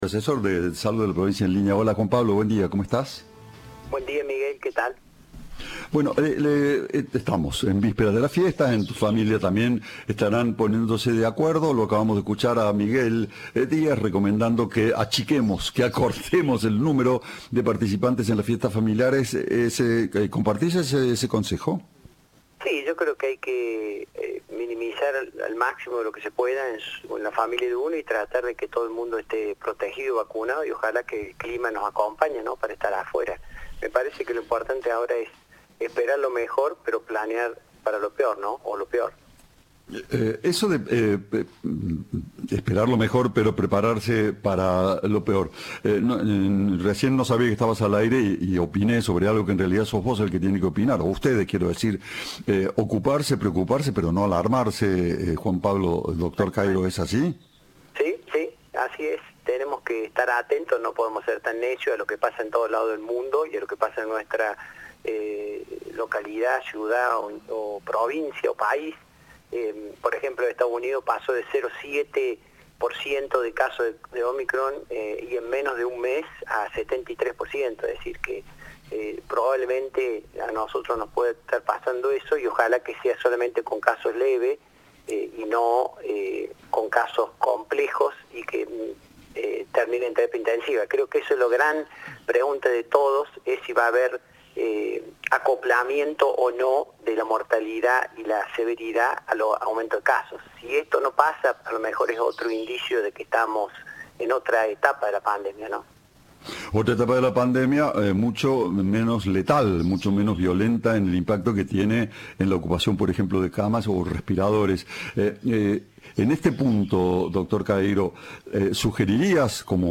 Informe de Turno Noche.